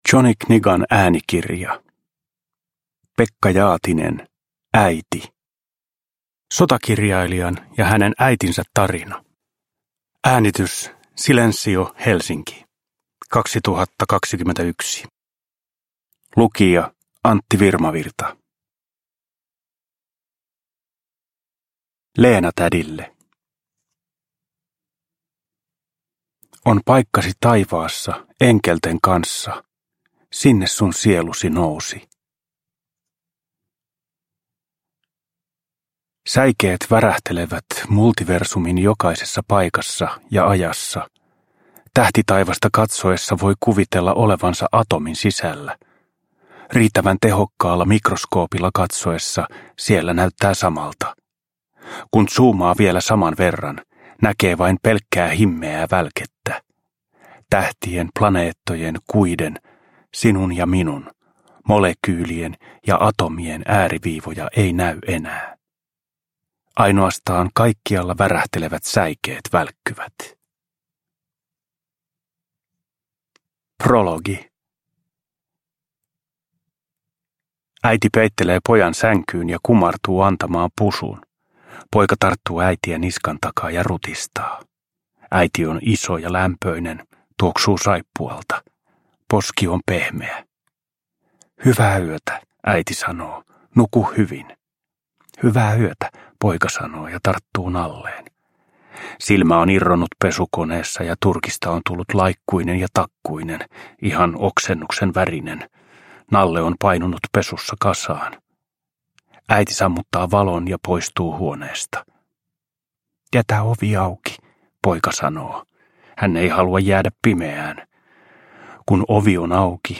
Äiti – Ljudbok
Uppläsare: Antti Virmavirta